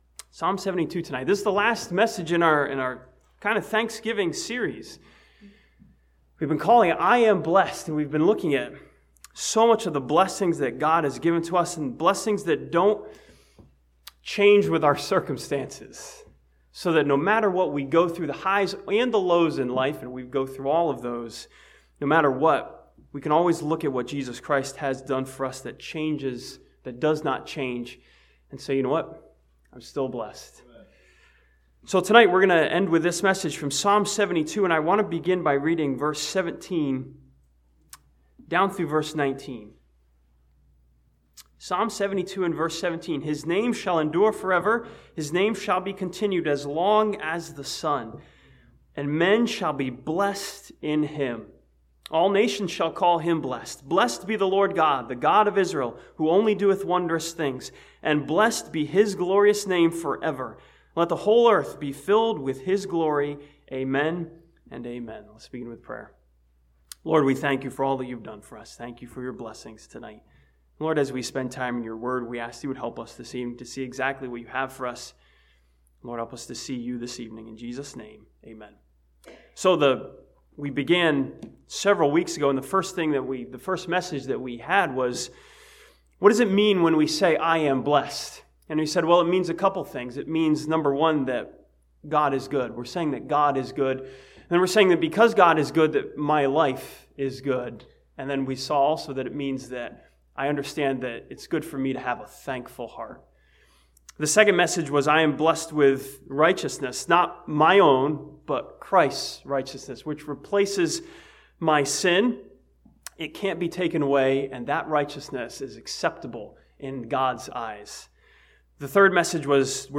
This sermon from Psalm 72 challenges believers to say "thank you" to the Lord for all the blessings He has given.